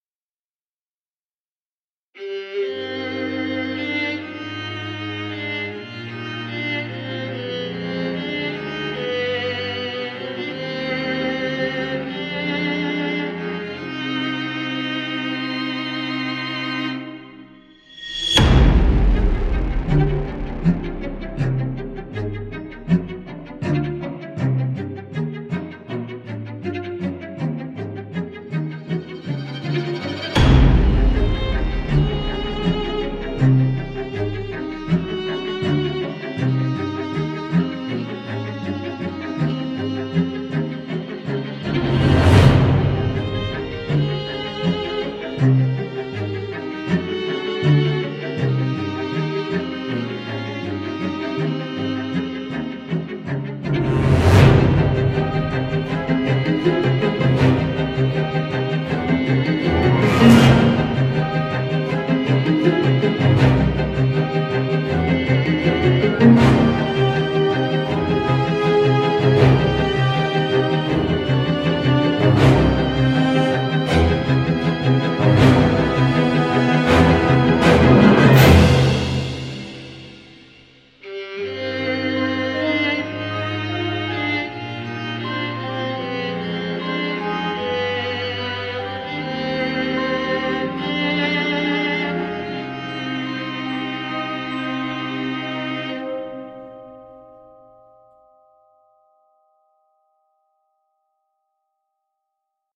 直观，以字符串为导向的LEGATO过渡
抒情系列弦乐五重奏乐器（除浪漫主义Guarnerius小提琴外）均分析您的演奏风格，并相应地处理所有三个颤音参数（数量，速度和淡入），以便为您提供最接近真实弦乐演奏者风格的近似值。
现实弓的变化
奏过渡RapidFire Spiccato，适用于快速，激进的短语。